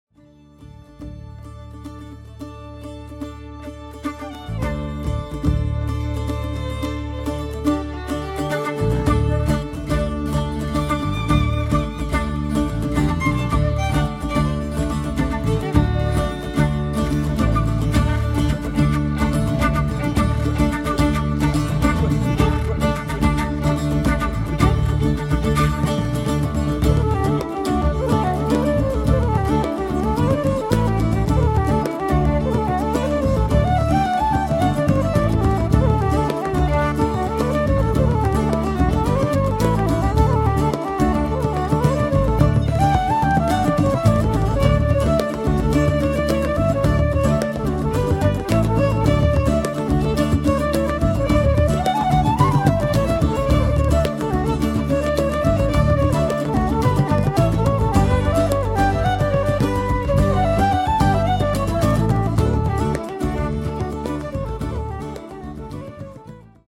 guitar, electric-guitar, bouzuki, vocals
bass, percussion